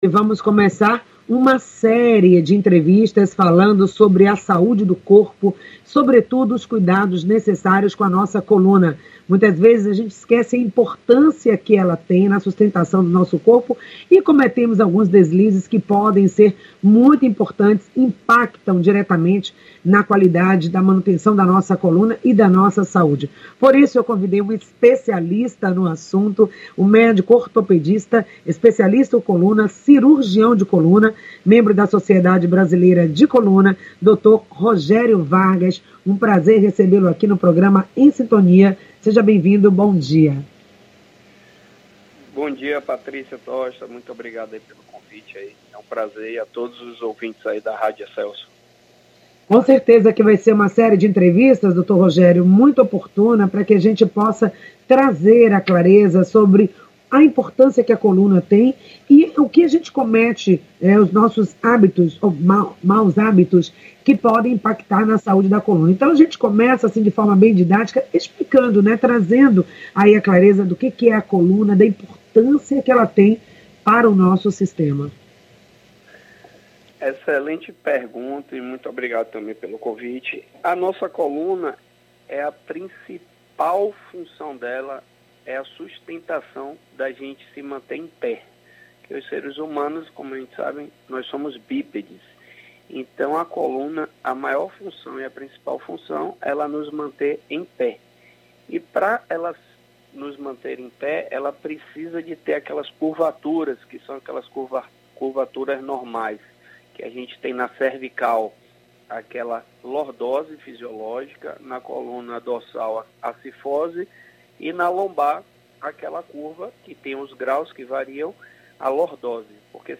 O Programa – Em Sintonia desta Sexta-feira, (08.04) iniciou uma série de entrevistas sobre o cuidado com o corpo, começando com atenção especial ao maior e mais importante conjunto de ossos de nosso corpo e que por isso, merece cuidados específicos: a coluna.